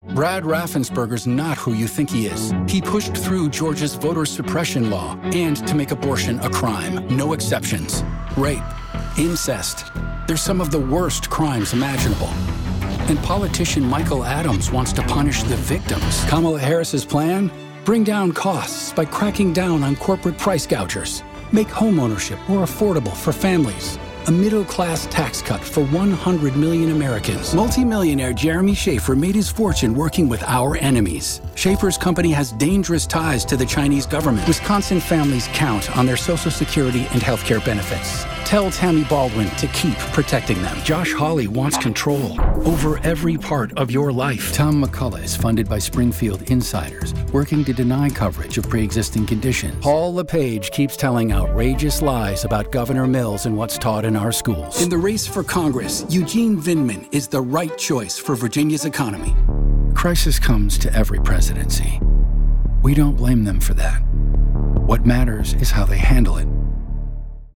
chicago : voiceover : narration : men